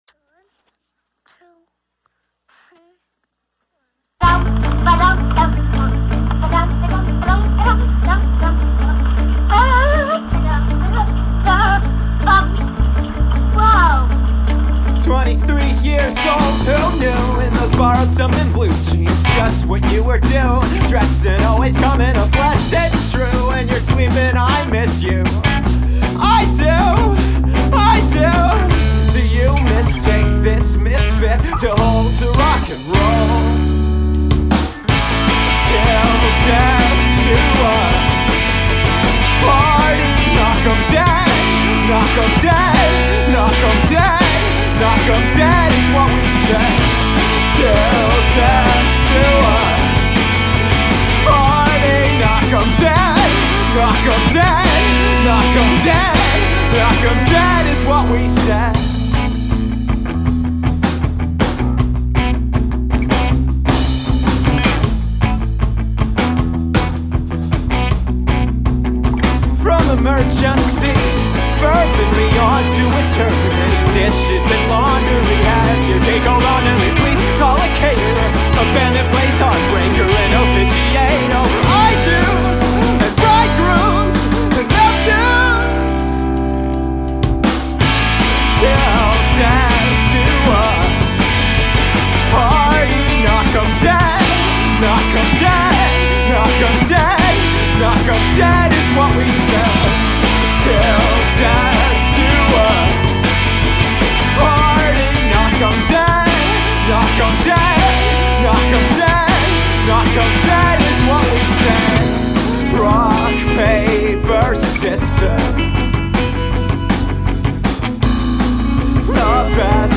guitar, bass, bckg voc
drums, voc
written and recorded in about four days.
-the end has the "here comes the bride" theme if you listen close.
dude, this sounds like a really good Ultimate Fakebook song. which is awesome. i love it. the chorus is just killer anthemic stock. makes me really happy.